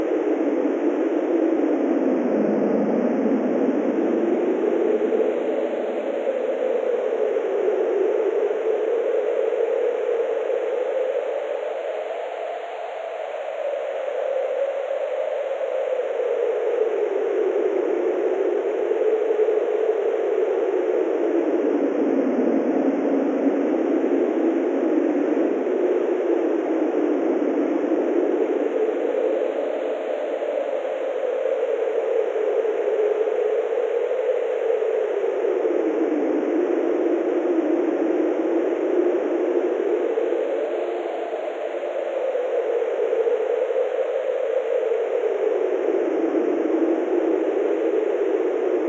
mod-sounds/sounds_wind.ogg at c2dac201ff72c49784a771e495742d732af9b1b6
sounds_wind.ogg